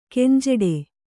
♪ kenjeḍe